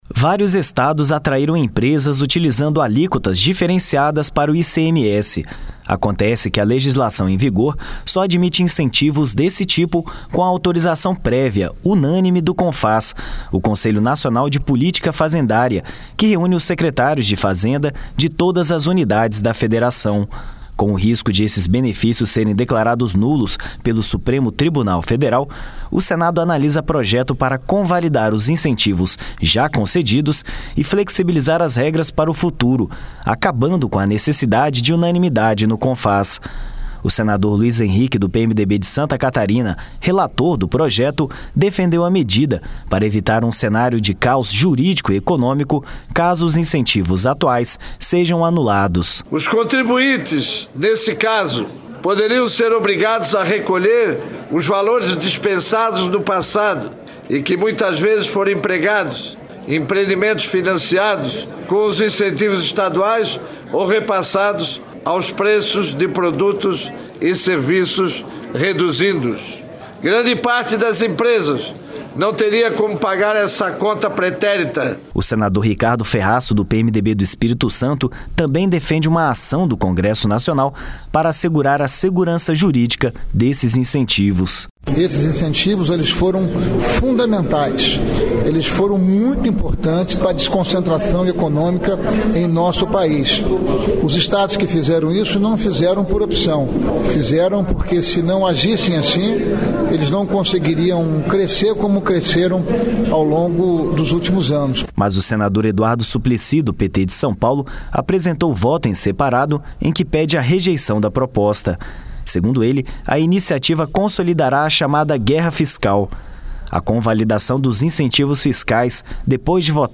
Com o risco de esses benefícios serem declarados nulos pelo Supremo Tribunal Federal, o Senado analisa projeto para convalidar os incentivos já concedidos e flexibilizar as regras para o futuro, acabando com a necessidade de unanimidade no Confaz. O senador Luiz Henrique, do PMDB de Santa Catarina, relator do projeto, defendeu a medida para evitar um cenário de caos jurídico e econômico caso os incentivos atuais sejam anulados.